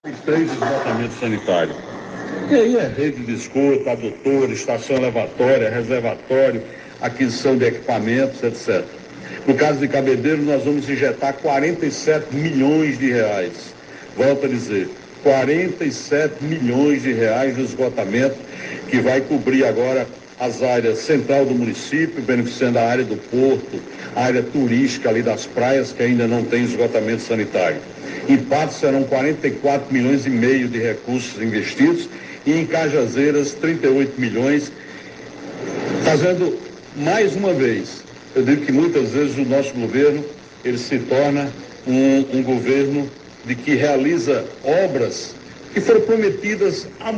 Durante o programa Conversa com o Governador, da rádio Tabajara, nesta segunda-feira, dia 23, o chefe do Poder Executivo da Paraíba, João Azevêdo, esteve prestando contas ao povo paraibano e anunciando novos investimentos pelo estado.